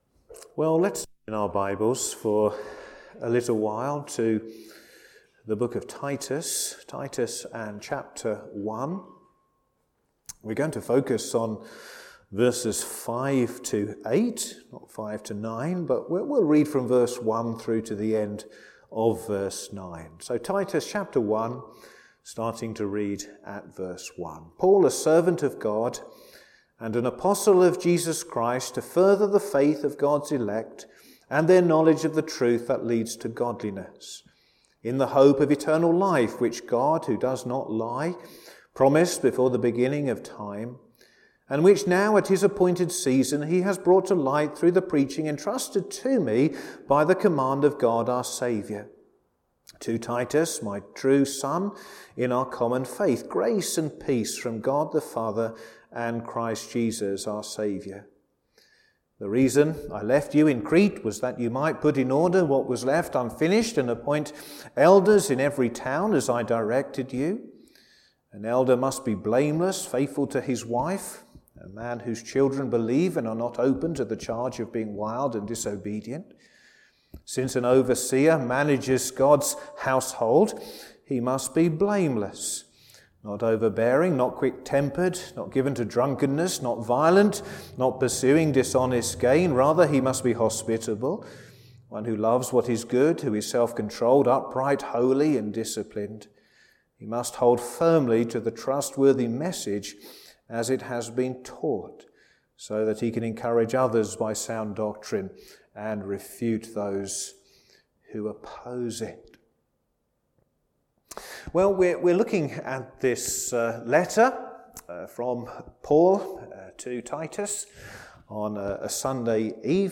Service Evening